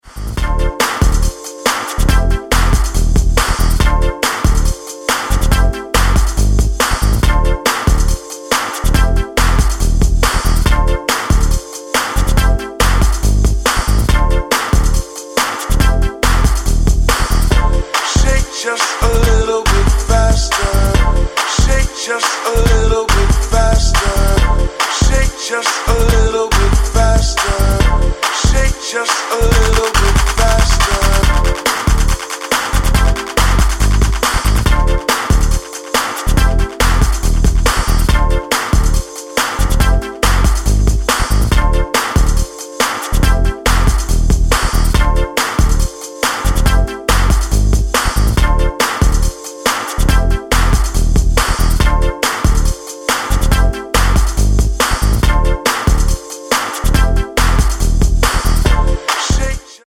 Detroit Electro Techno